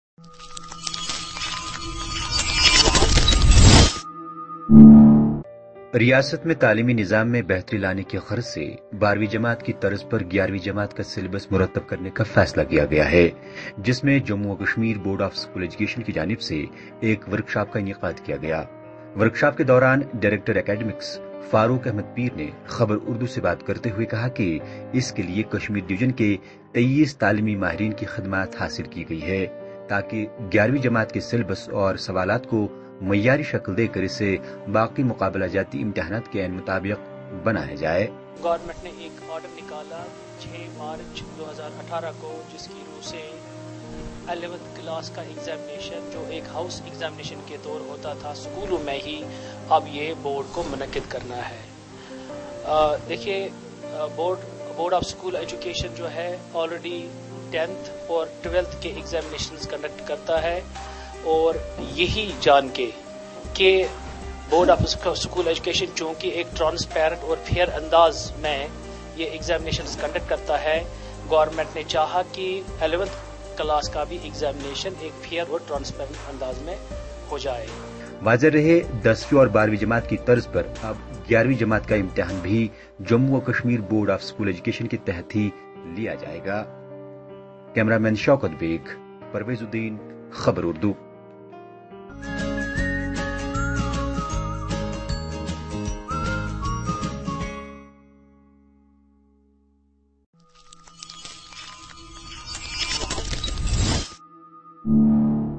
Bord-Of-School-Workshop.mp3